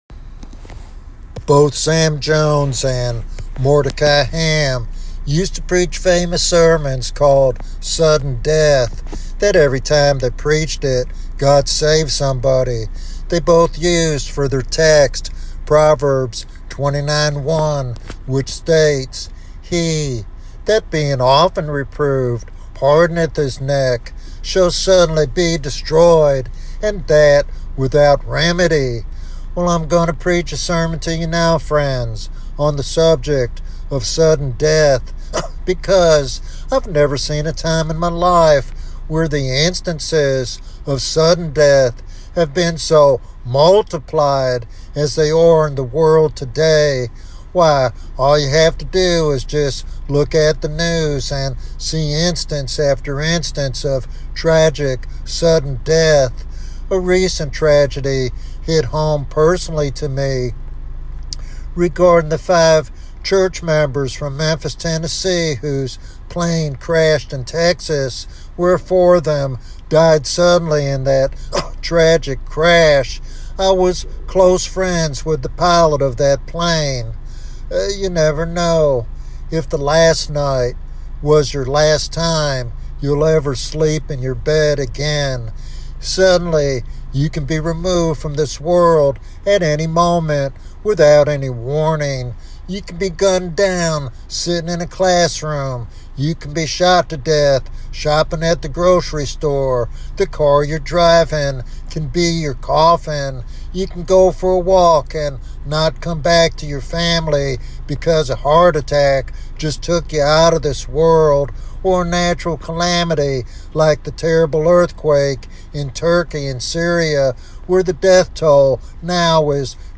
In this powerful evangelistic sermon
Sermon Outline